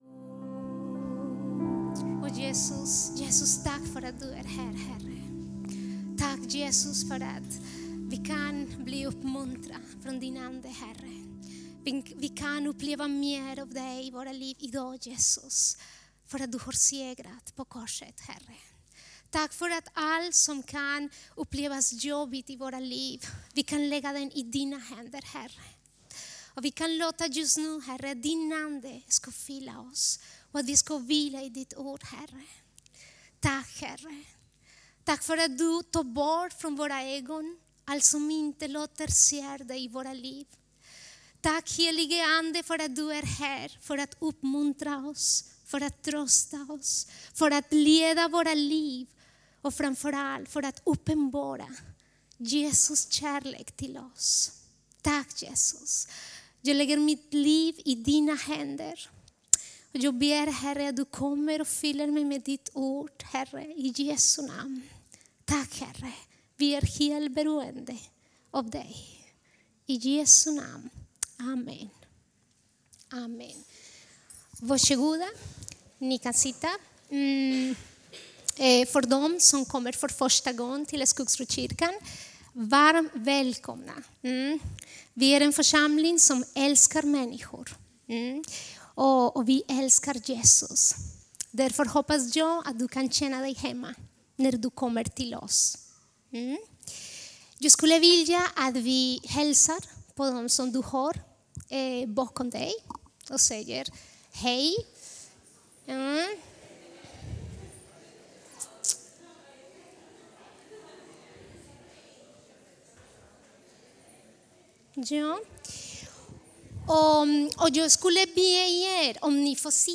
Fristående predikan HT 2015